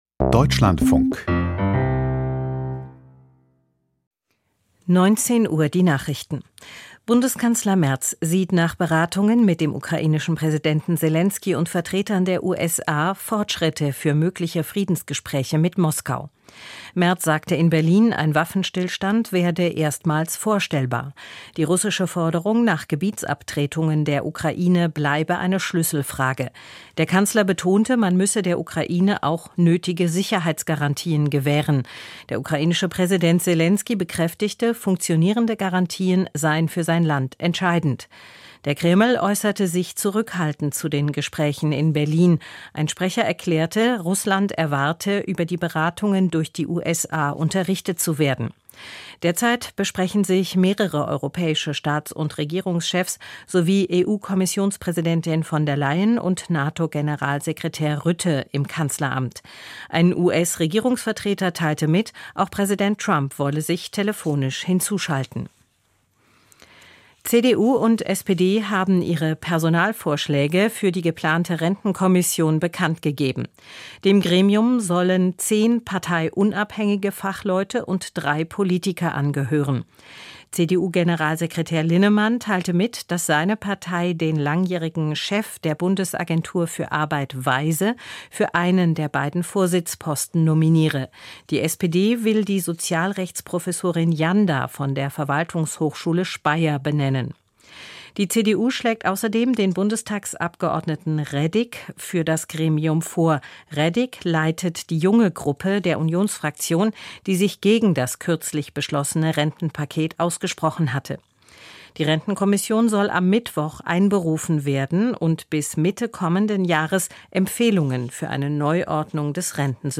Die Nachrichten vom 15.12.2025, 19:00 Uhr